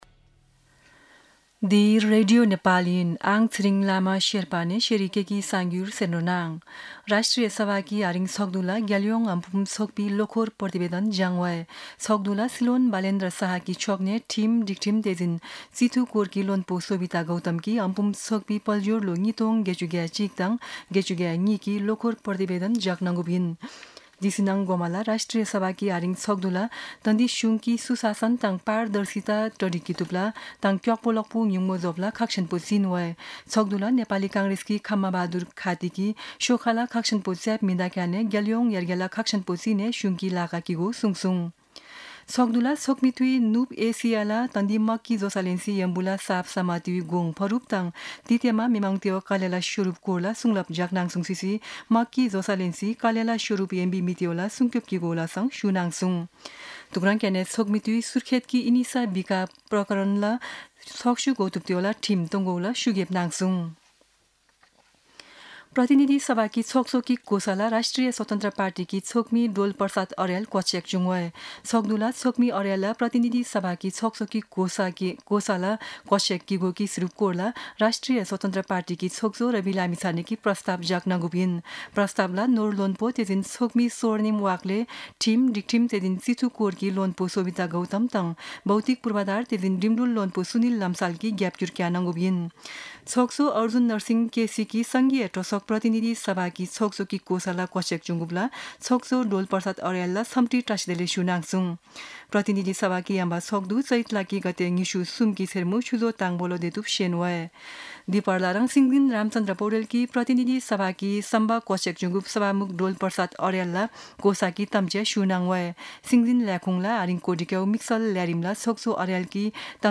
शेर्पा भाषाको समाचार : २२ चैत , २०८२
Sherpa-News-22.mp3